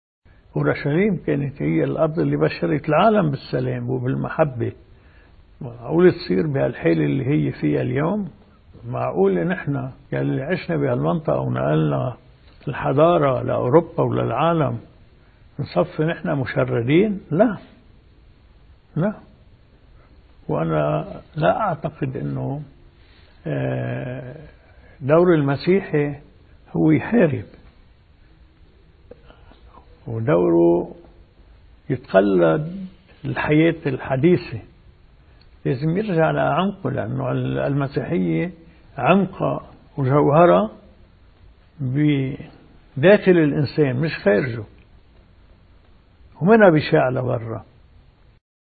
مقتطف من حديث الرئيس ميشال عون لمحطة “SAT 7” لمناسبة حلول عيد الفصح: